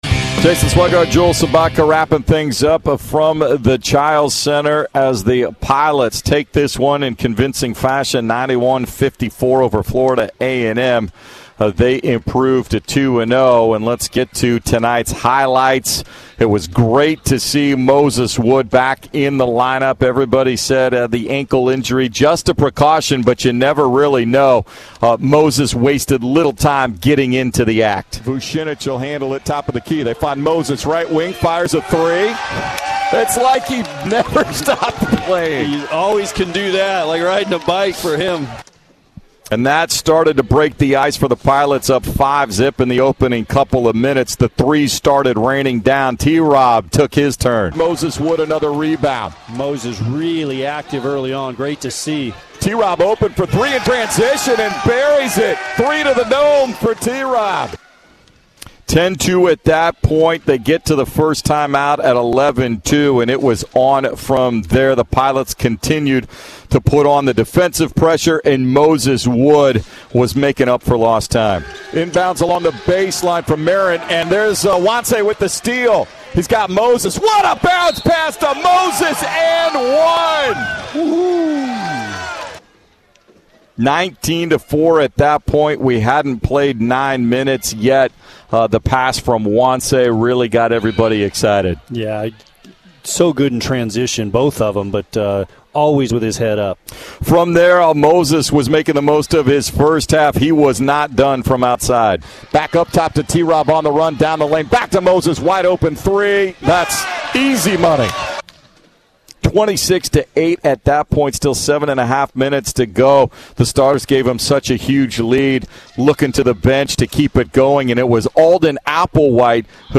November 09, 2022 Radio highlights from Portland's 91-54 win over Florida A&M on Wednesday, Nov. 9, 2022. Courtesy of 910 ESPN Portland (KMTT).